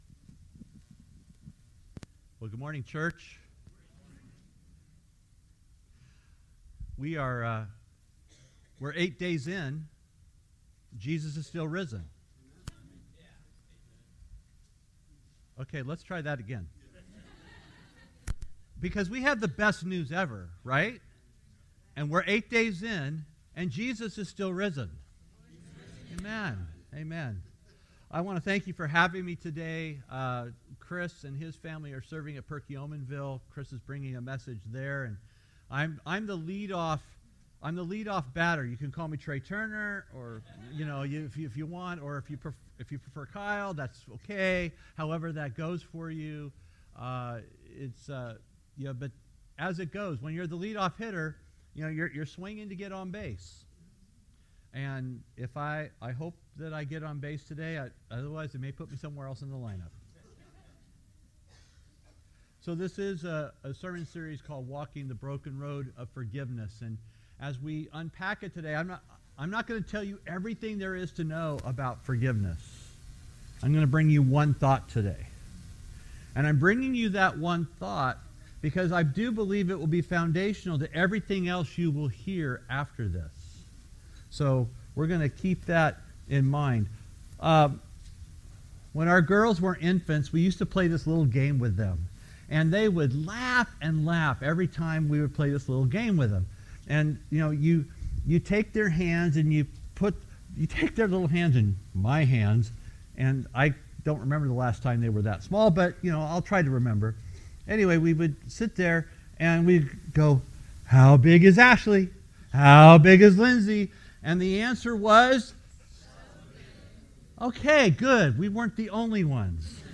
Finland Mennonite Church - Walking the Broken Road of Forgiveness - Sermon Series